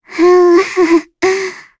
Lavril-Vox-Laugh.wav